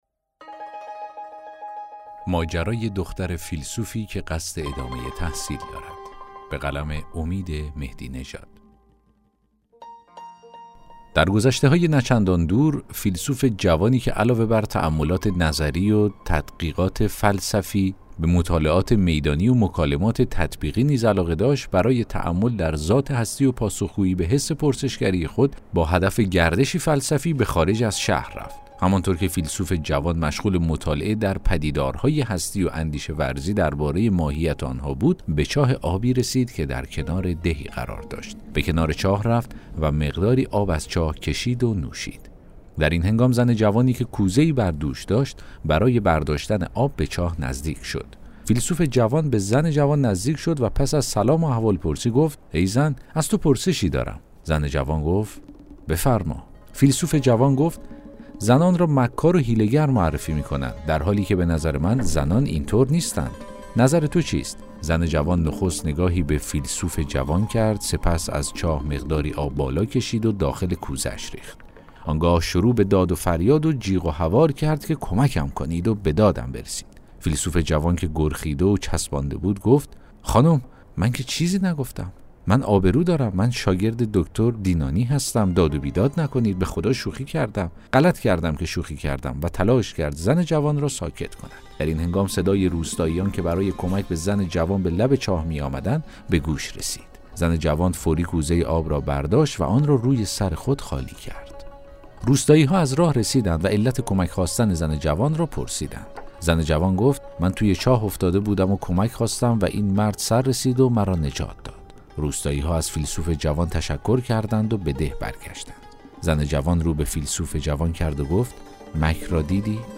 داستان صوتی: ماجرای دختر فیلسوفی که قصد ادامه تحصیل دارد